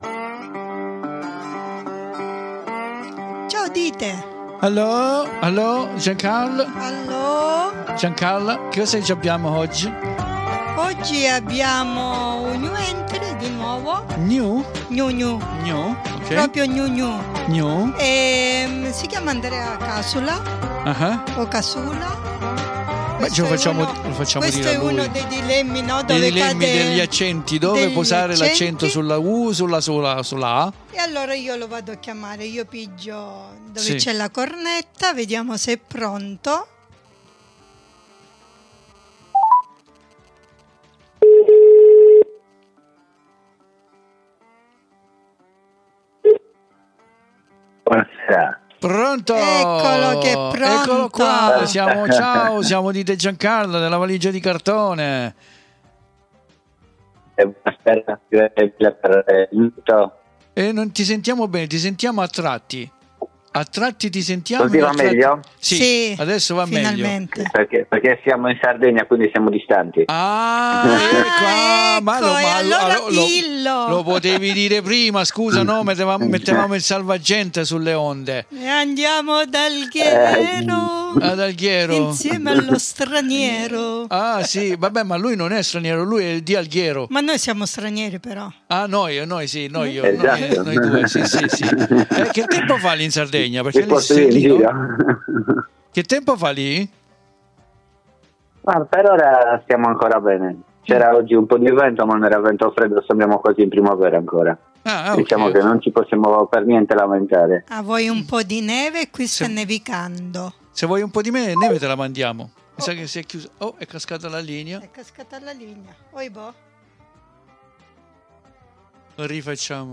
INTERVISTANO